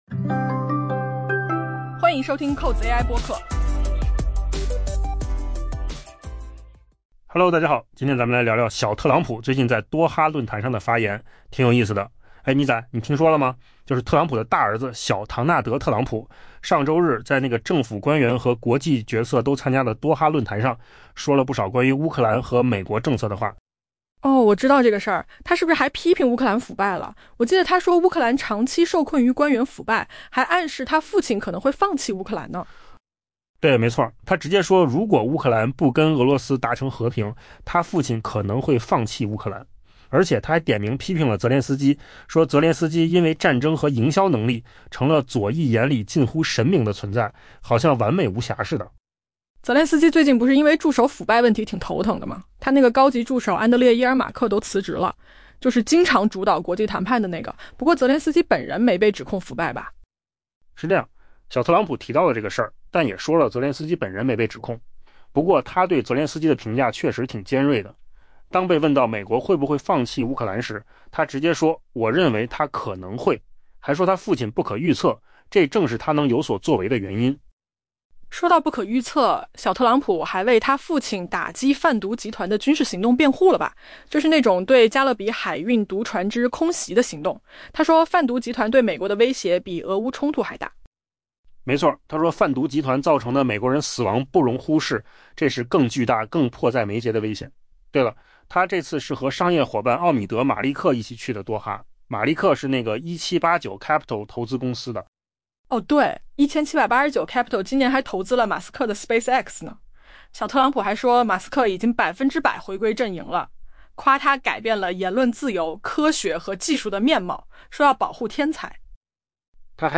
AI 播客：换个方式听新闻 下载 mp3 音频由扣子空间生成 据 Politico 报道，美国总统特朗普的长子小唐纳德·特朗普 （Donald Trump Jr.）